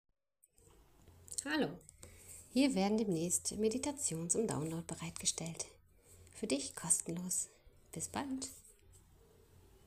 Meditationen zum Entspannen
Sternenhimmel Meditation